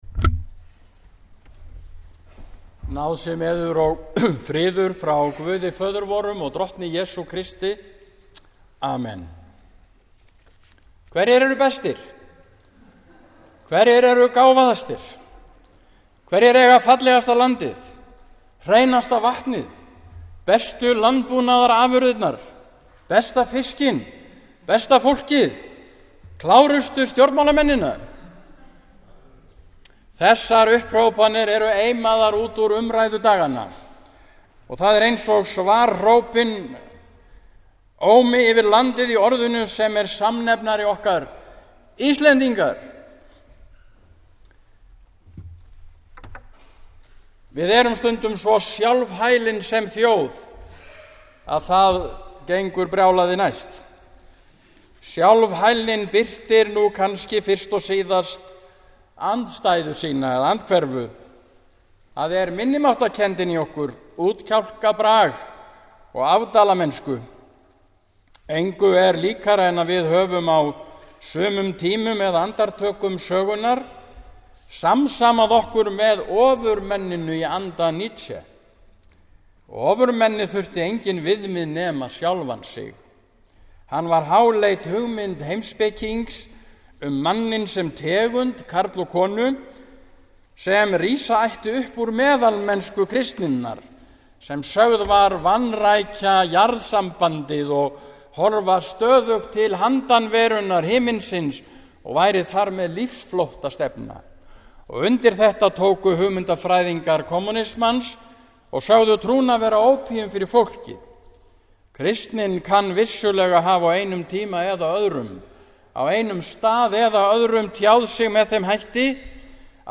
sunnudaginn 31. ágúst 2014 – 11. sd. e. trinitatis